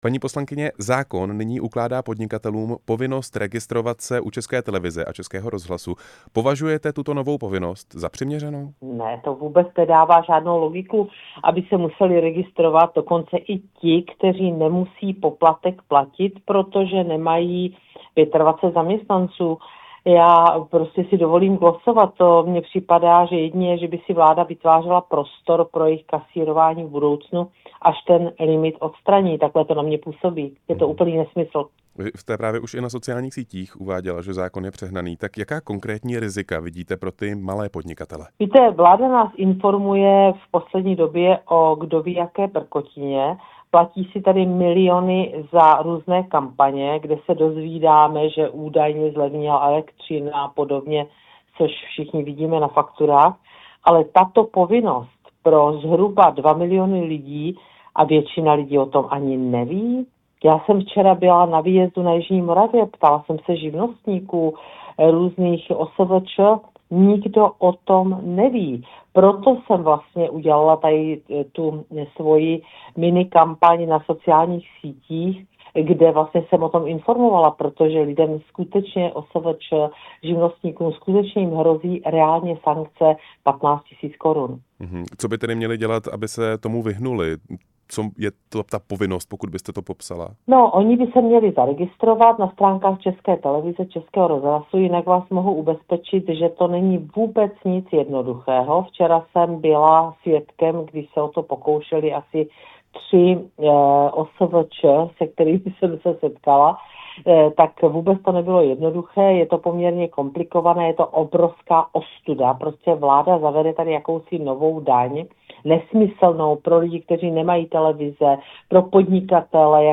Opozice opatření kritizuje jako přehnané a administrativně zatěžující. Hostem vysílání Radia Prostor byla Alena Schillerová, předsedkyně poslaneckého klubu hnutí ANO.
Rozhovor s poslankyní ANO Alenou Schillerovou